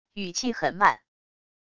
语气很慢wav音频